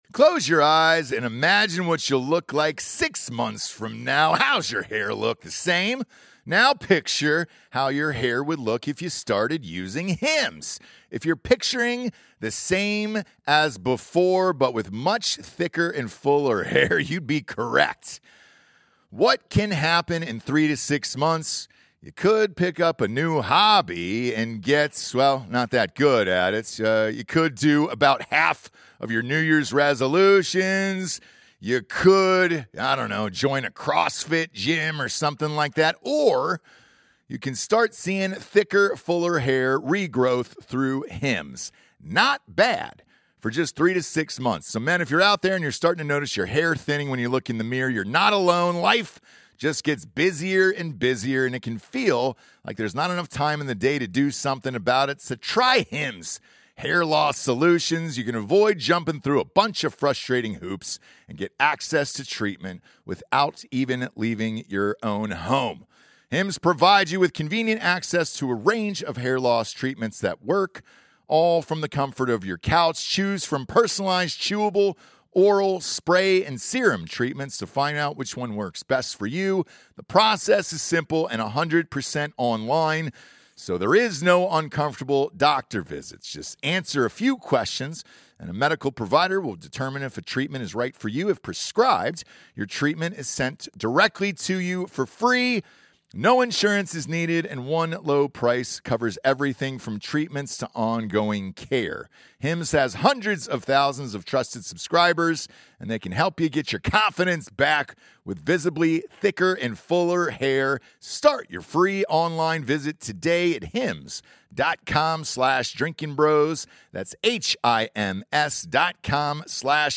Episode 857 - Special Guest Philosopher Peter Boghossian